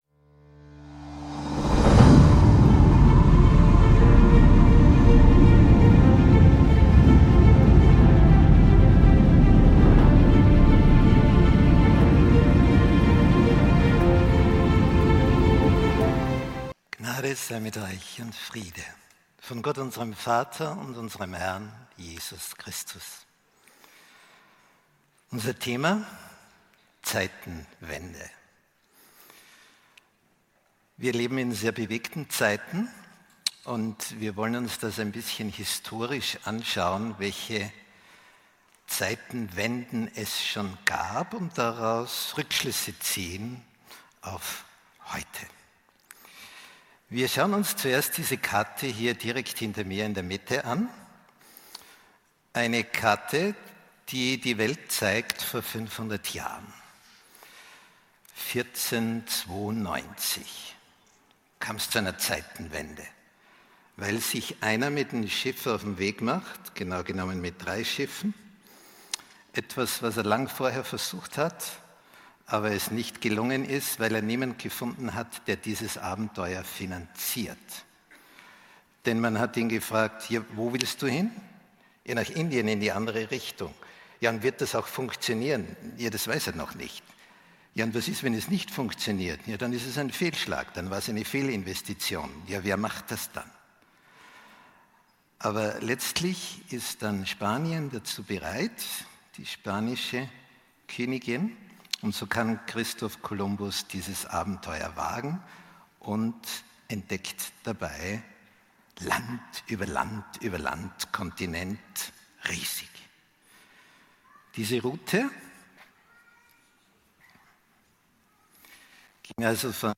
Entdecken Sie in diesem fesselnden Vortrag die historischen Zeitenwenden, die unsere Gegenwart prägen. Von Kolumbus’ Entdeckung bis zur französischen Revolution beleuchtet der Referent, wie göttliche und menschliche Handlungen die Weltgeschichte beeinflussen.